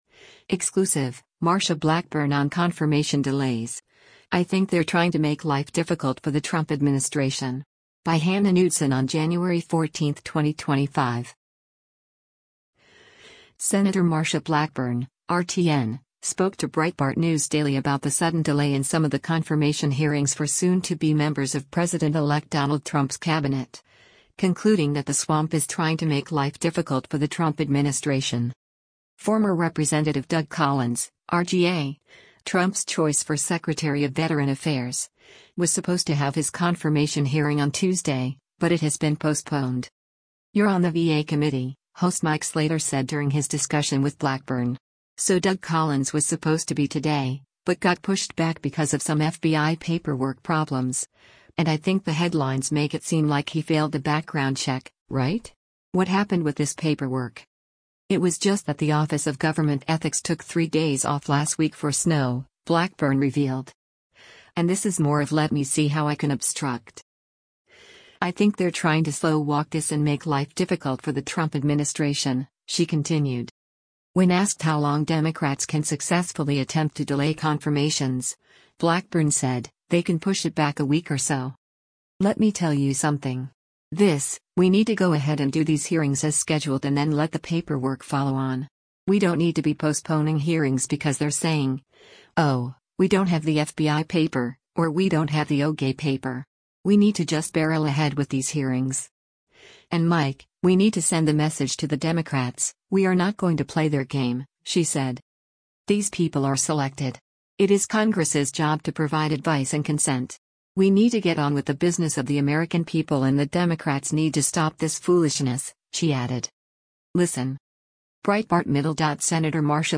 Sen. Marsha Blackburn (R-TN) spoke to Breitbart News Daily about the sudden delay in some of the confirmation hearings for soon-to-be members of President-elect Donald Trump’s Cabinet, concluding that the swamp is trying to “make life difficult for the Trump administration.”
Breitbart News Daily airs on SiriusXM Patriot 125 from 6:00 a.m. to 9:00 a.m. Eastern.